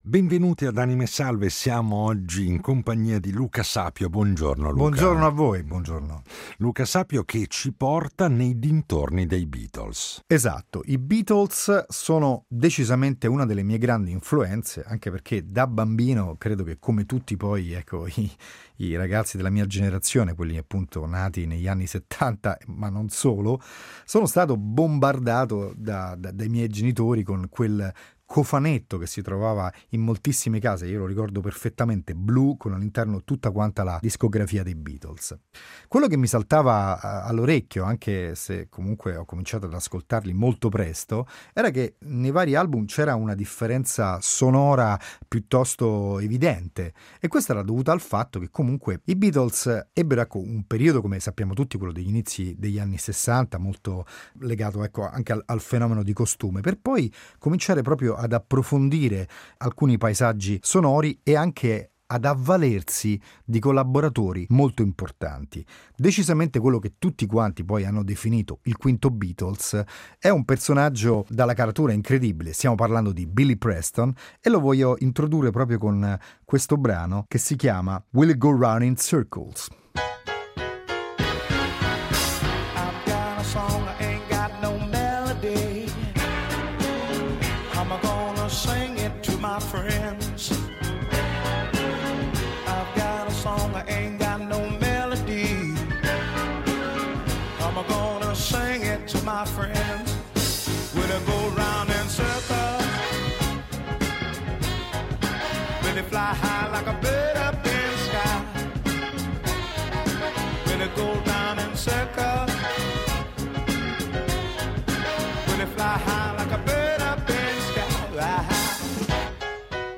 I suoi itinerari sonori per “Anime Salve” sono impreziositi da una serie di registrazioni inedite che chiudono ogni puntata, realizzate con la sua “ Italian Royal Family” nel suo studio di Roma.